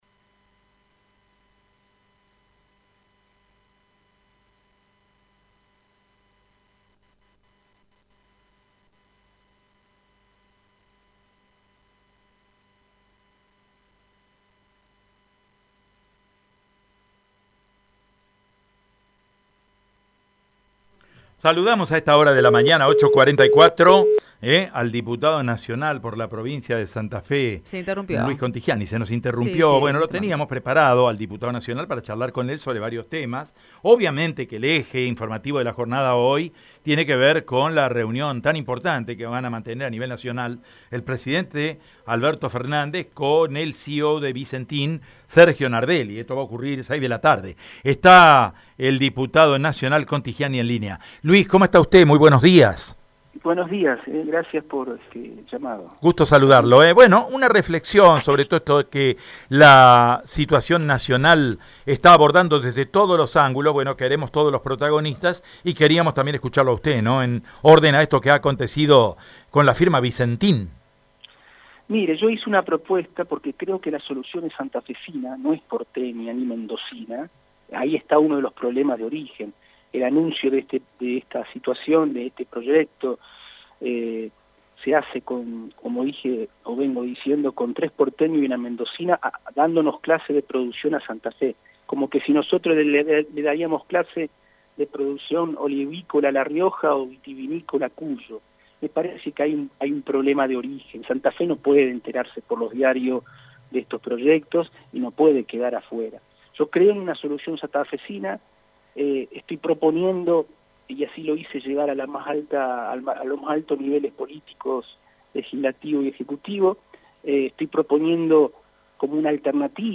Luis Contigiani en Radio EME: